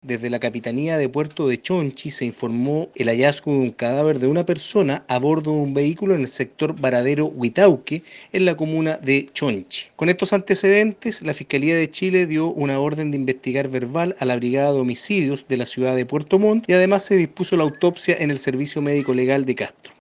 Con relación a los trámites investigativos los explicó el fiscal de turno del Ministerio Público, Cristian Mena.